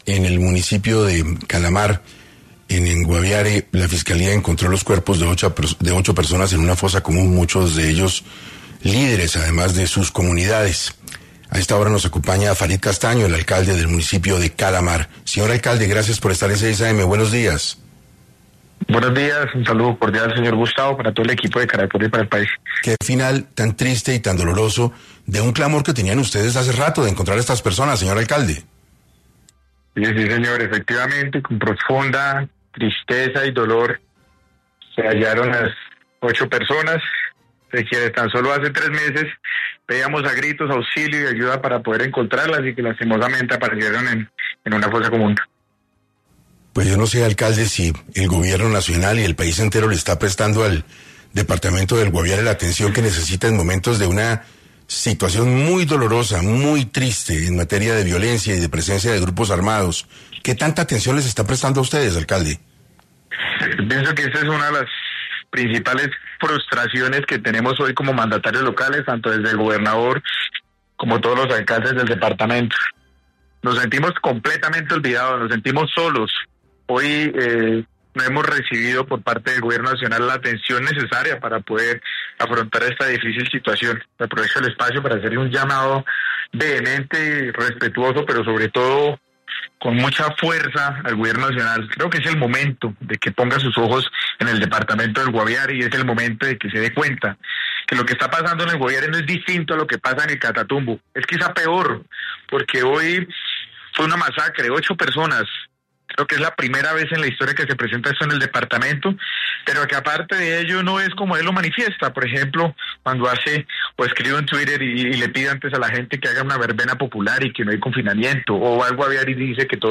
En Caracol Radio estuvo Farid Castaño, alcalde del municipio de Calamar, Guaviare